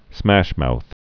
(smăshmouth)